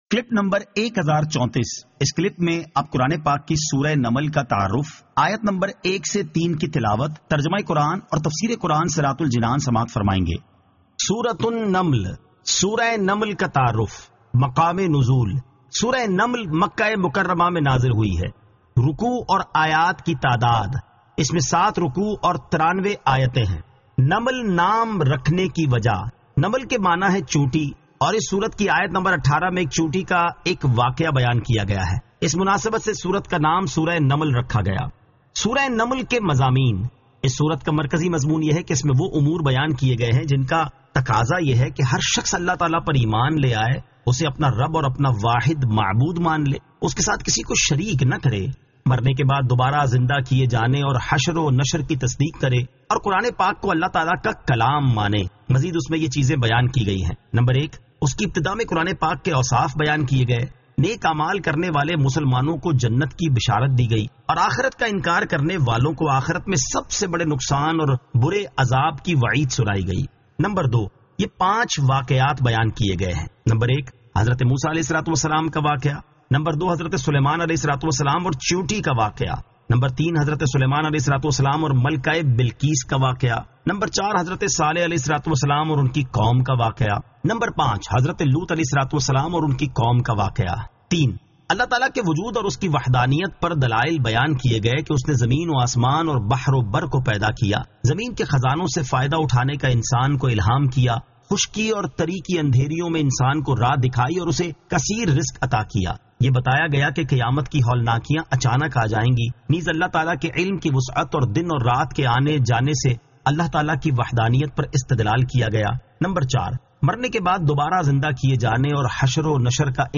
Surah An-Naml 01 To 03 Tilawat , Tarjama , Tafseer